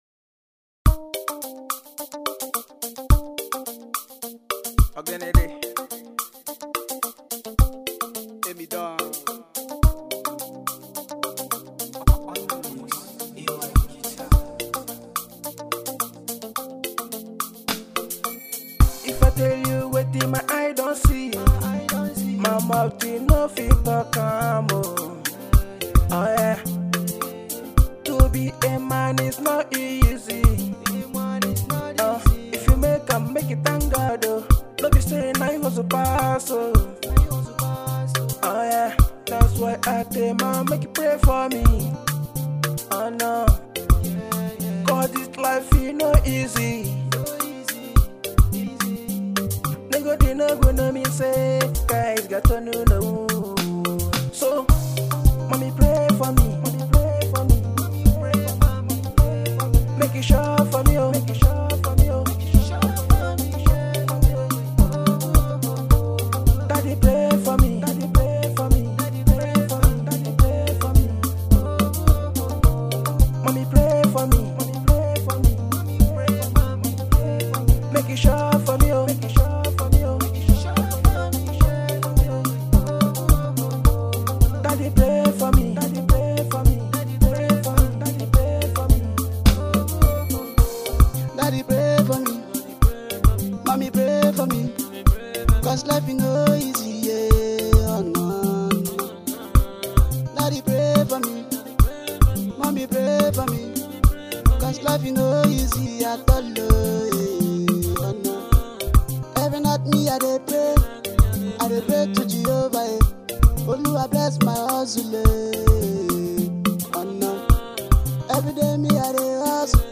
Afrobeat,Hip pop